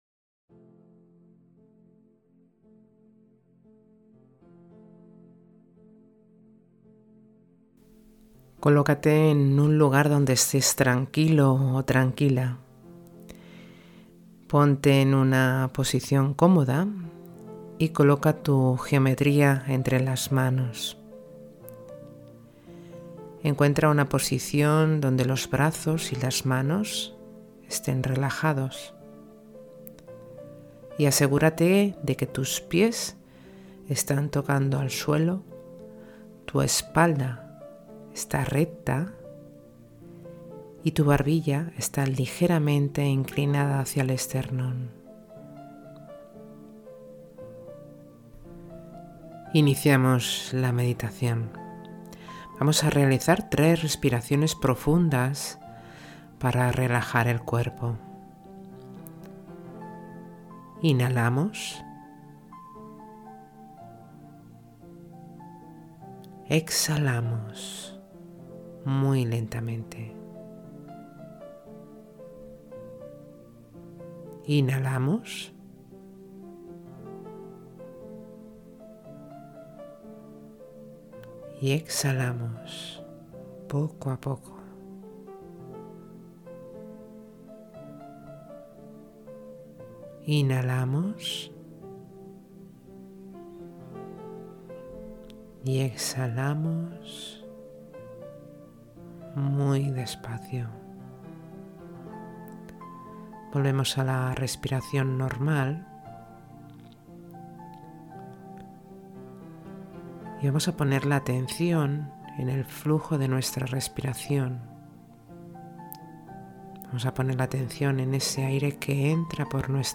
Meditación Básica Octaedro Truncado